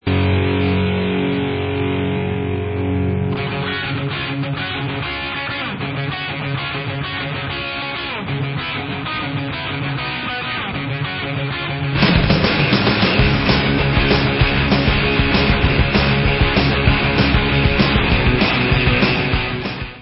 sledovat novinky v oddělení Heavy Metal